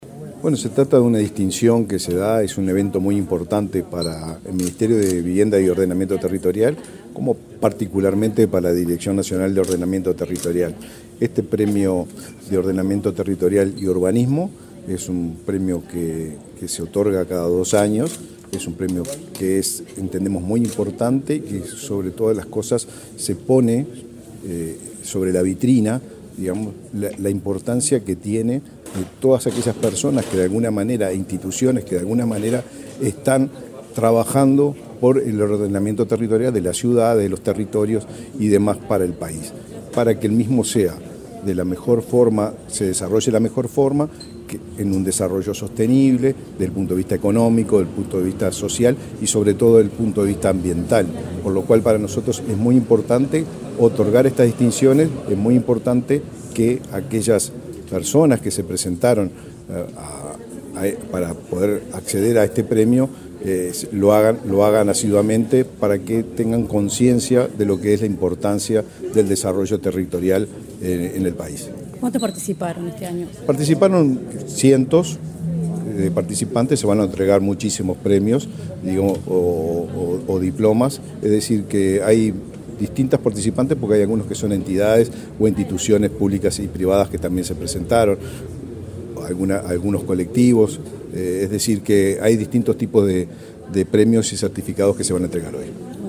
Declaraciones del ministro de Vivienda, Raúl Lozano
El ministro de Vivienda, Raúl Lozano, dialogó con Comunicación Presidencial, antes de participar, este miércoles 8 en Montevideo, en el acto de